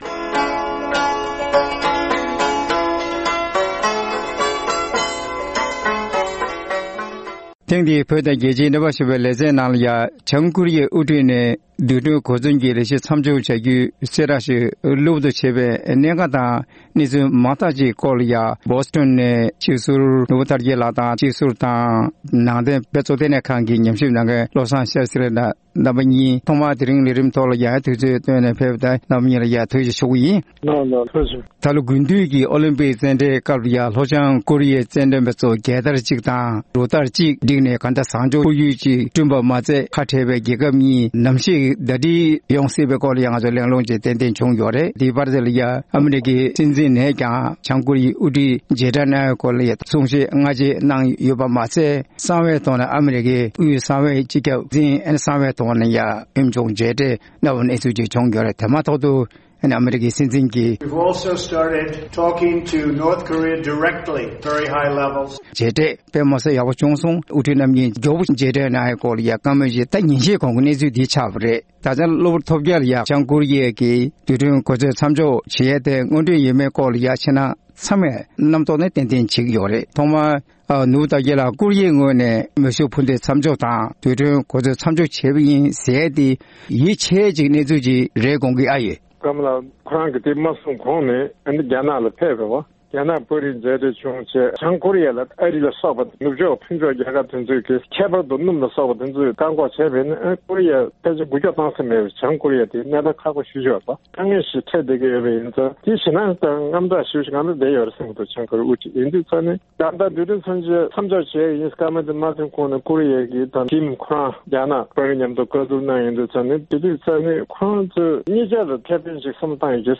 ལྷོ་བྱང་ཀོ་རི་ཡའི་དབར་འགྲིག་འཇགས་ཀྱི་ཁོར་ཡུག་སྐྲུན་བཞིན་པ་དང་འབྲེལ་ཡོད་གནས་ཚུལ་ཐད་གླེང་མོལ།